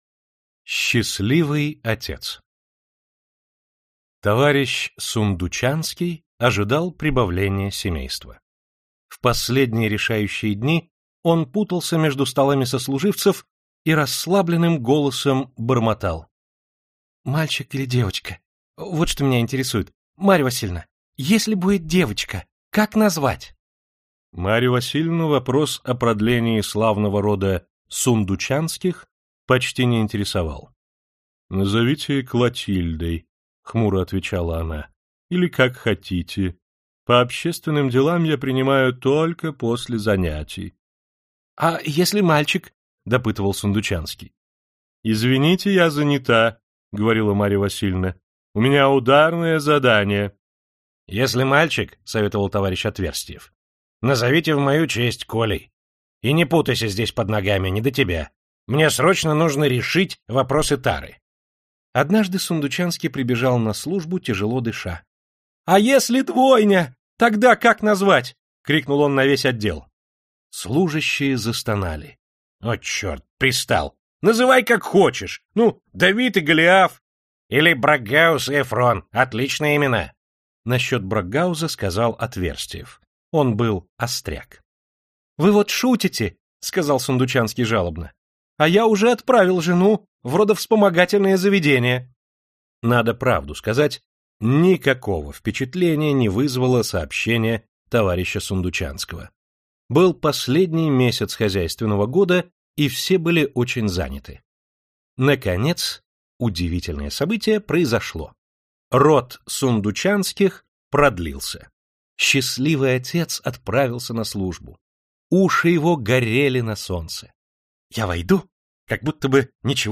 Аудиокнига Колумб причаливает к берегу и другие рассказы | Библиотека аудиокниг